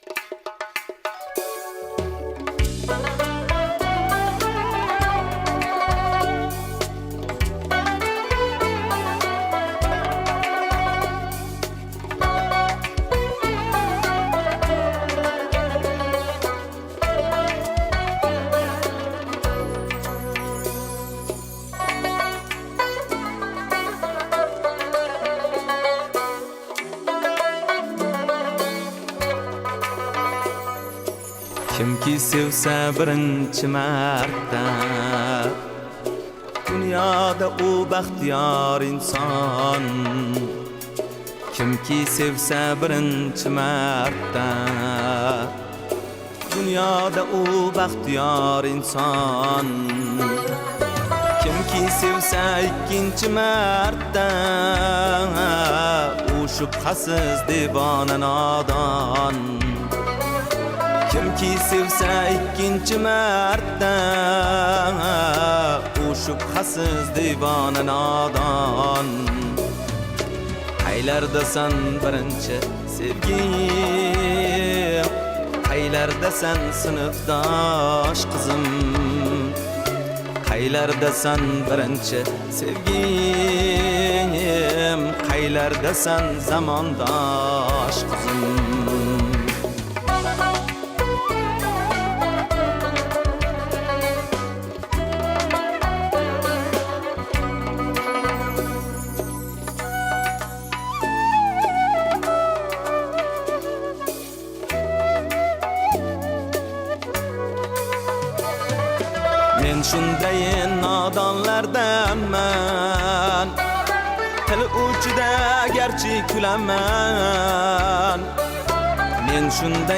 песню